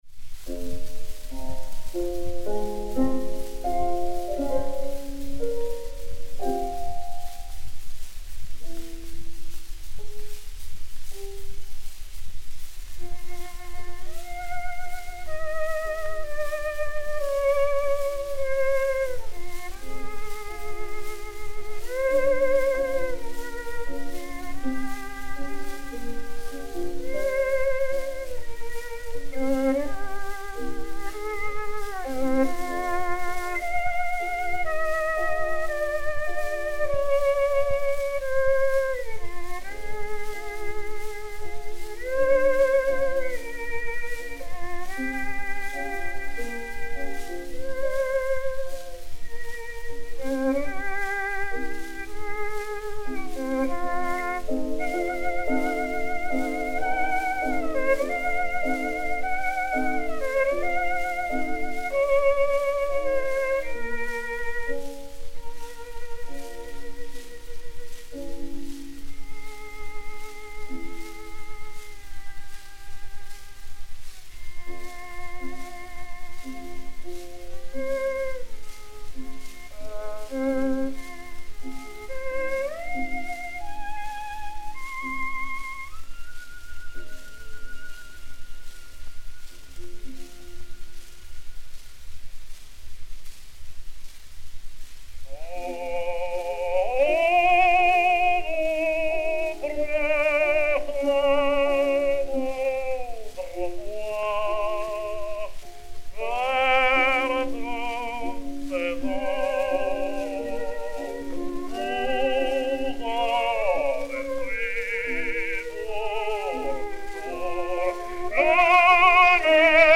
avec accompagnement de violoncelle et de piano
ténor
violon
piano